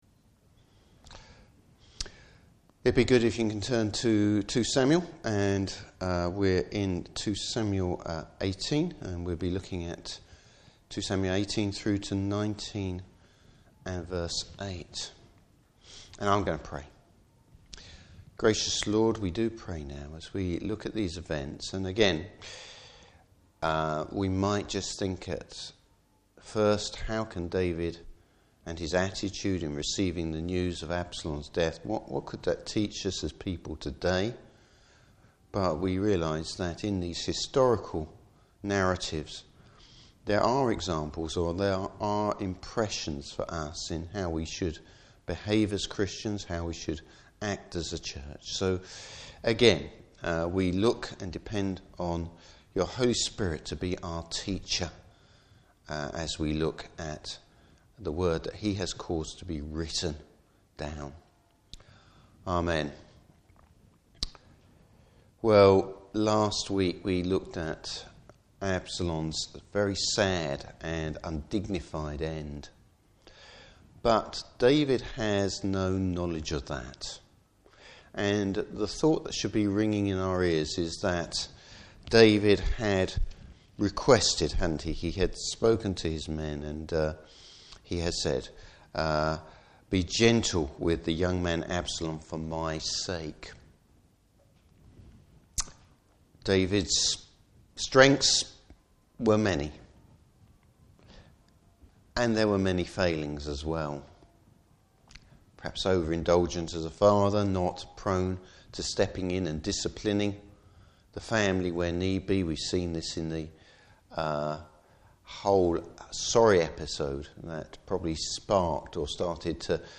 Service Type: Evening Service The hard word David needs to hear!